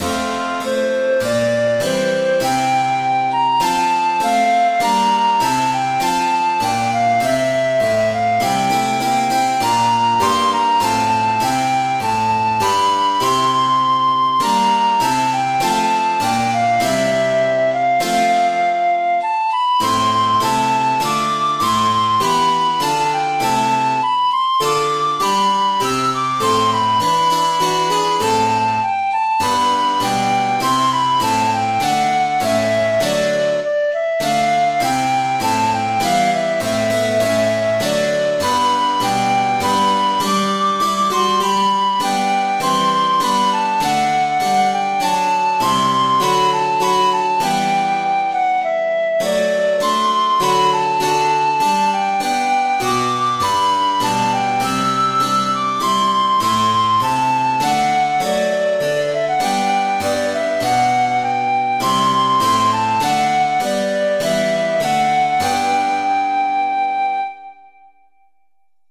kleine Stücke für Flöte und Klavier und einen Kanon.